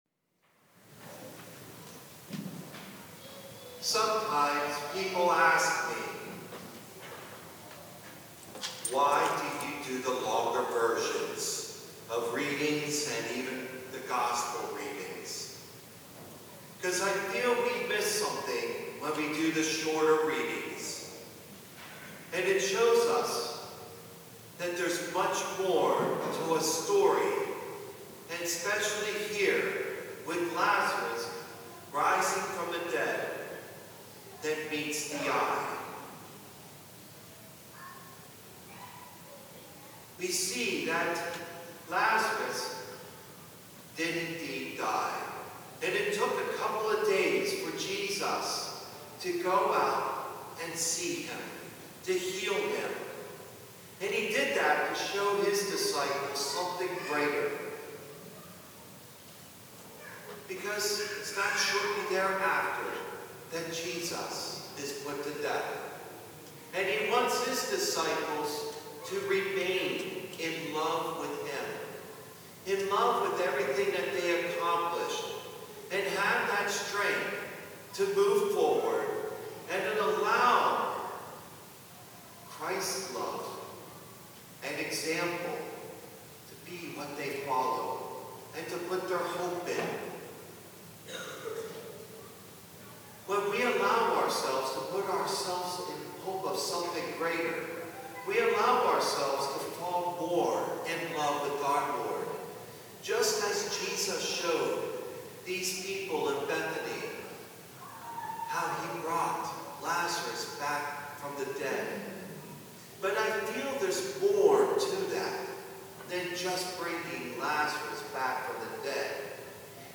homily0406.mp3